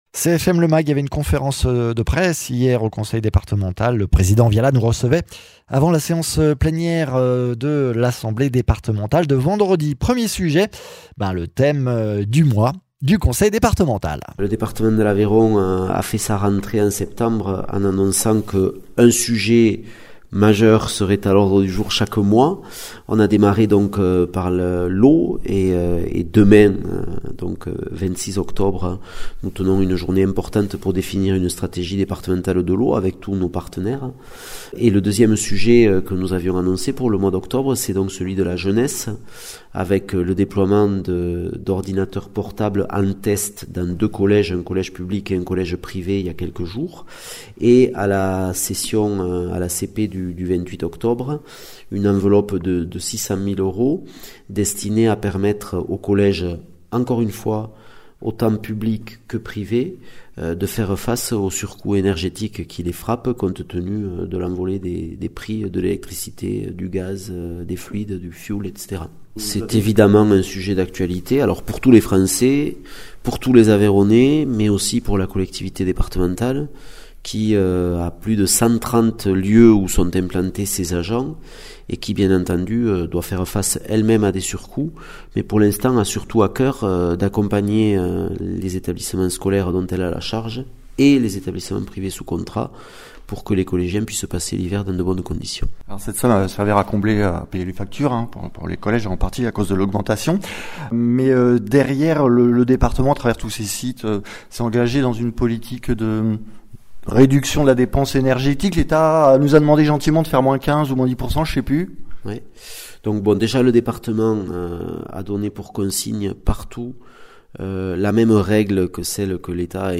Interviews
Invité(s) : Arnaud Viala, Président du conseil départemental de l’Aveyron